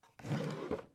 DrawerOpen.wav